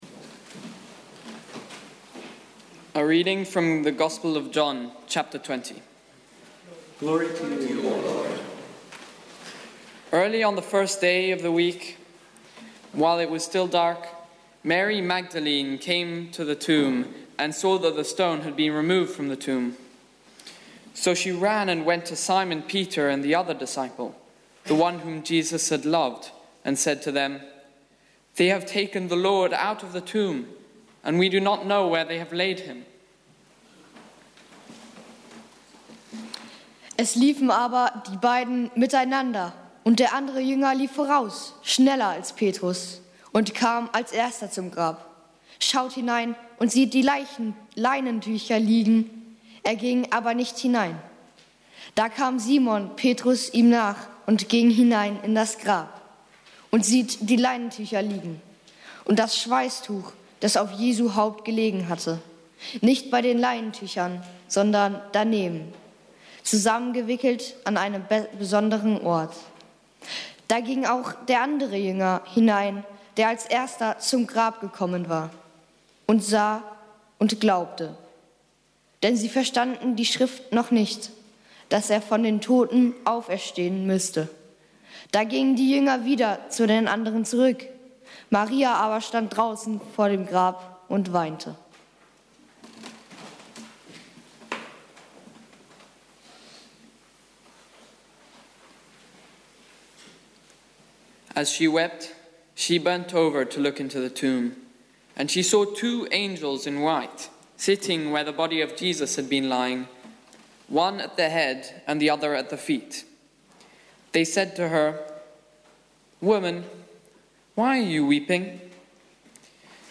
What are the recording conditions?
Easter Vigil / Osternacht 2017